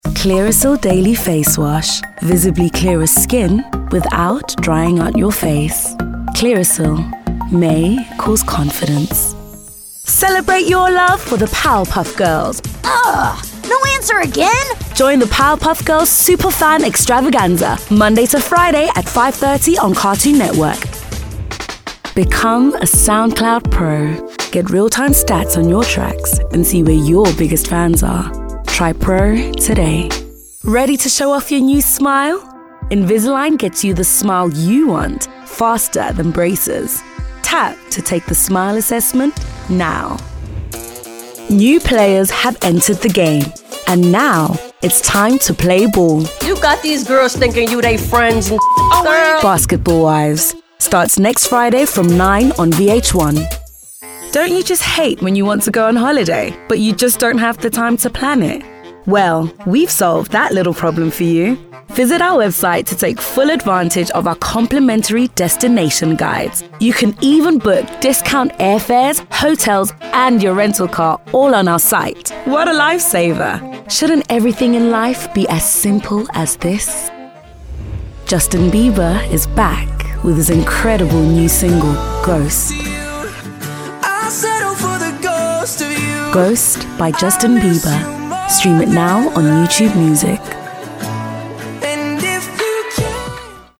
Vocal Styles:
comforting, gentle, inviting, warm
My demo reels